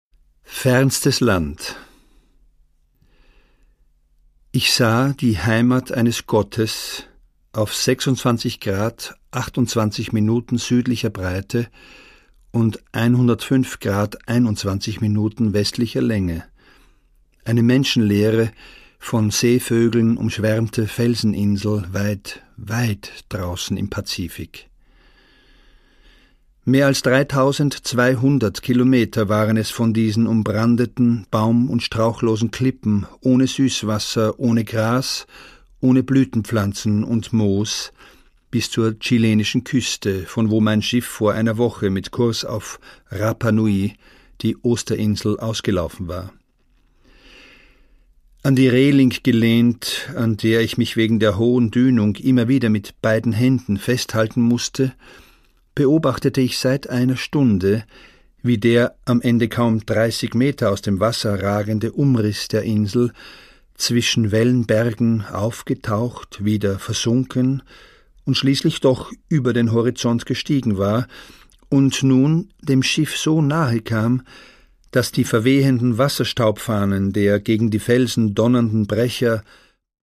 Produkttyp: Hörbuch-Download
Fassung: Autorenlesung
Gelesen von: Christoph Ransmayr